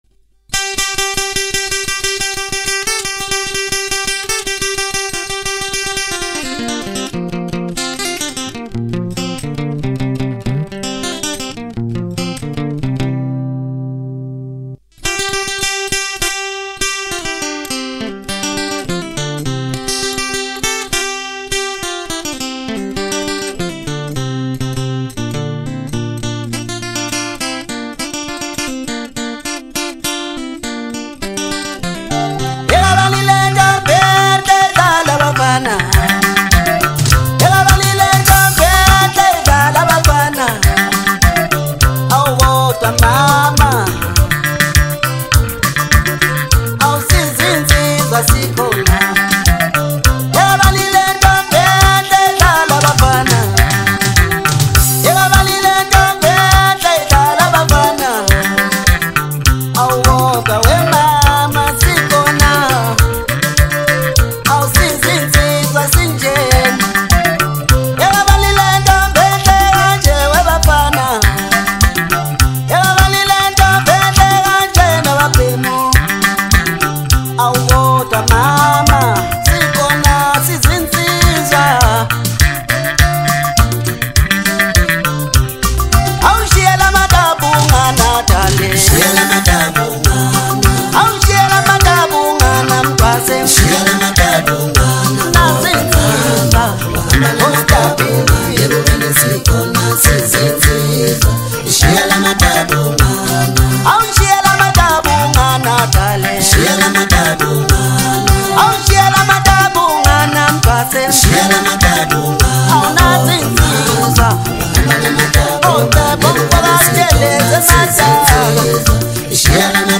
Home » Amapiano » DJ Mix » Maskandi
South African Maskandi singer